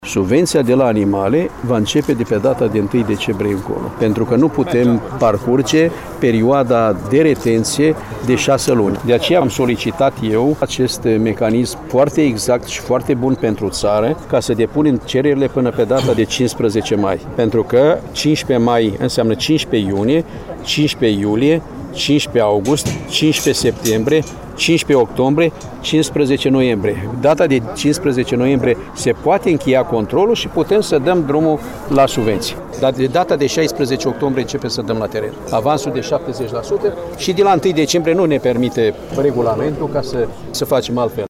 Anunţul a fost făcut, ieri, în comuna Leţcani, judeţul Iaşi, de către ministrul agriculturii, Petre Daea.